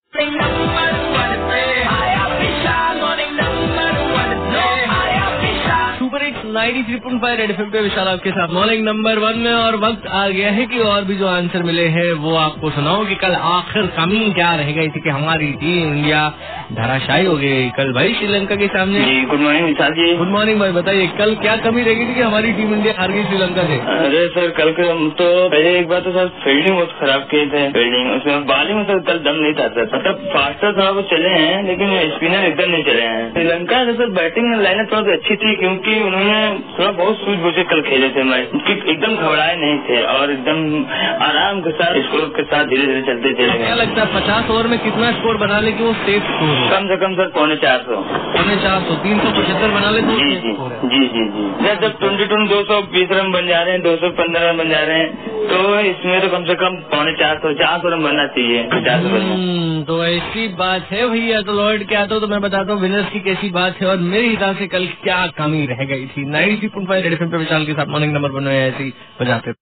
CALLER'S BYTE